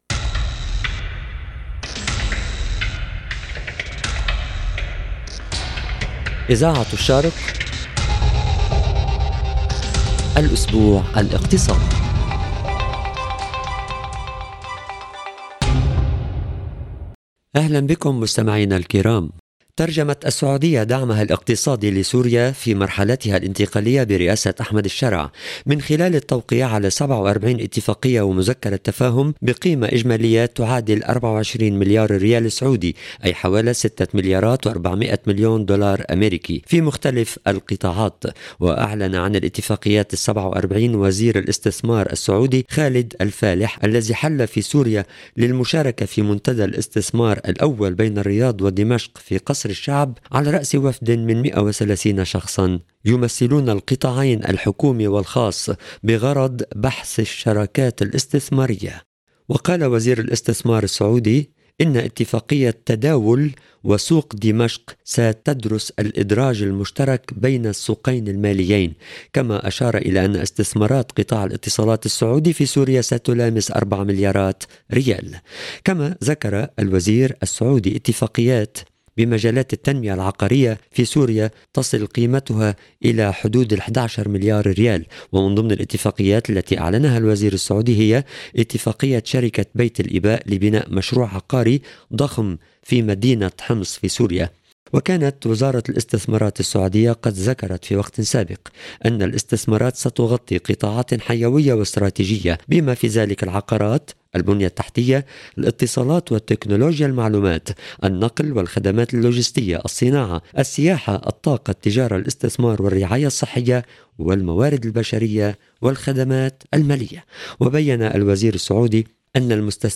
عناوين النشرة